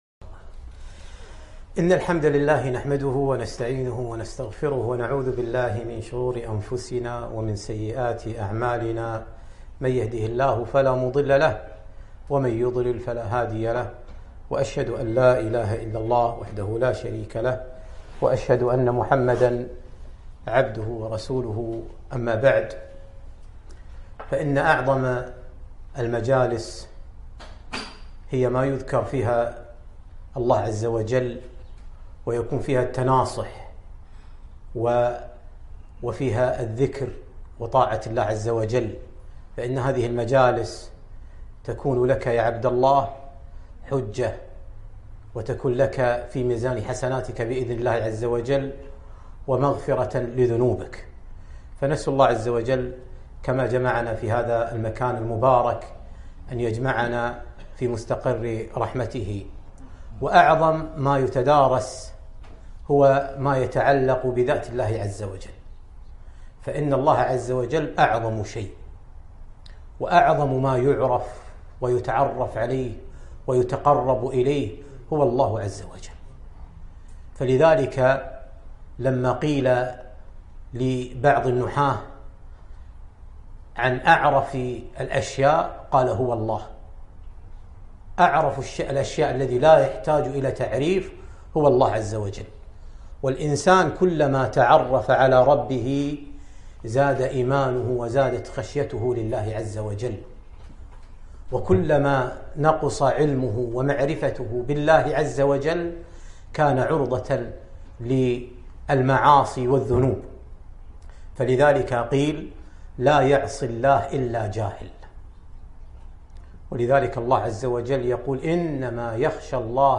محاضرة عن اسم الله العفوّ